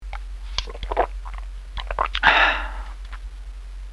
SFX喝汽水发出的音效下载